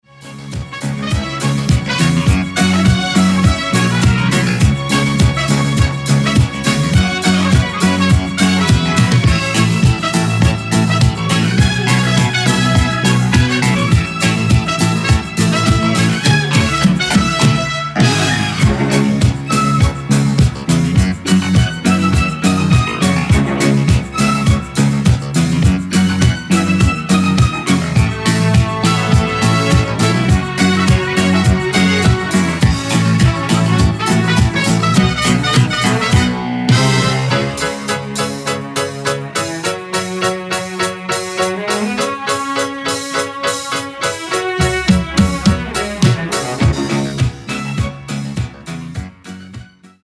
Funky, Afro, Soul Groove e JazzFunk Anni 70 e 80.